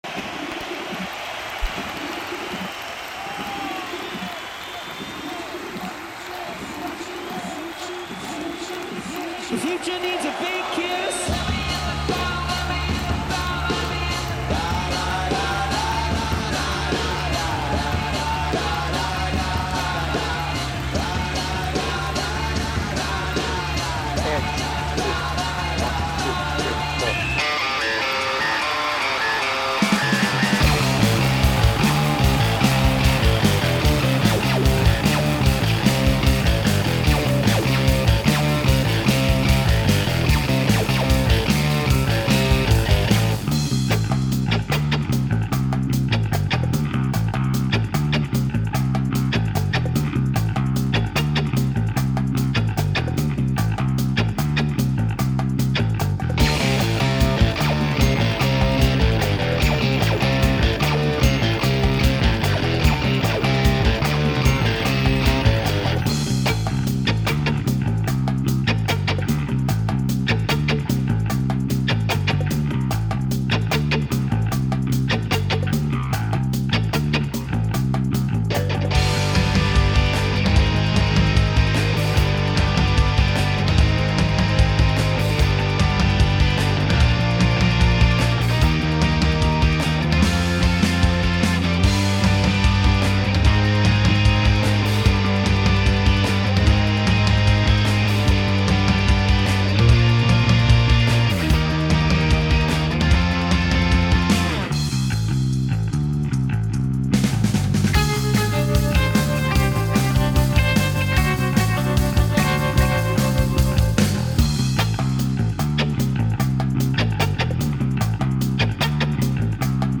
Based on 360° Tour and album